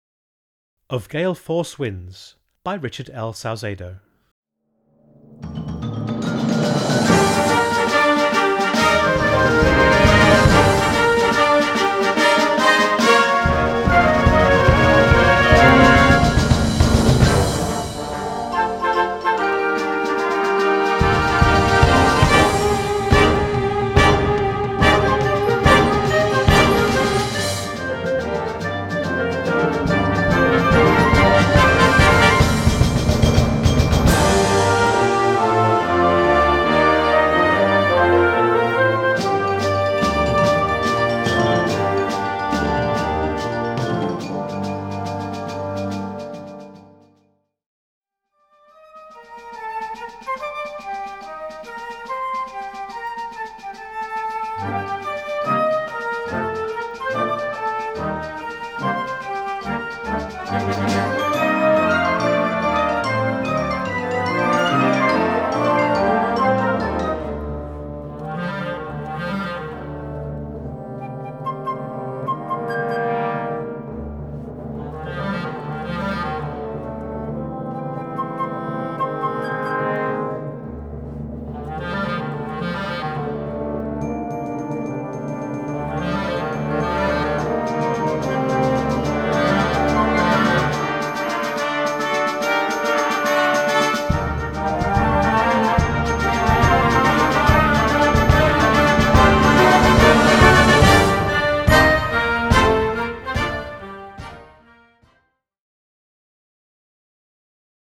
Categorie Harmonie/Fanfare/Brass-orkest
Subcategorie Hedendaagse muziek (1945-heden)
Bezetting Ha (harmonieorkest)